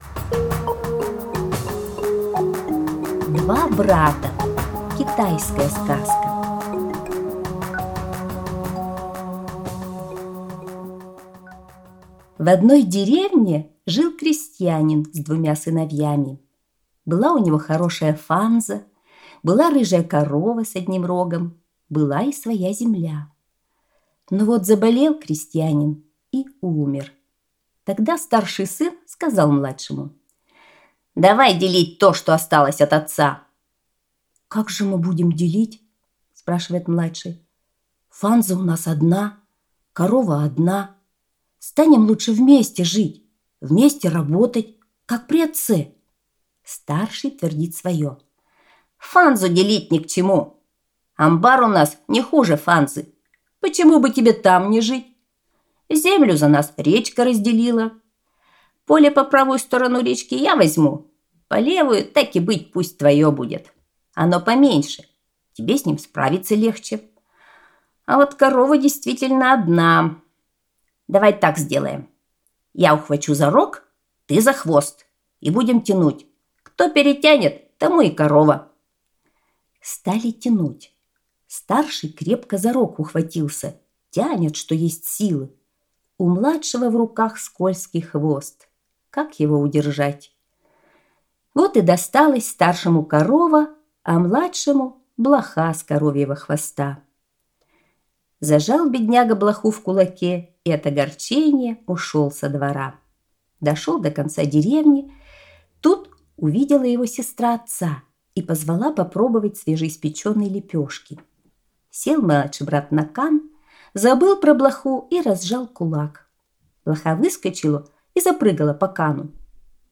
Два брата – китайская аудиосказка